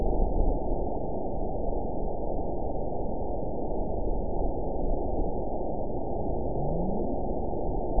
event 914110 date 04/28/22 time 04:06:14 GMT (3 years ago) score 9.45 location TSS-AB05 detected by nrw target species NRW annotations +NRW Spectrogram: Frequency (kHz) vs. Time (s) audio not available .wav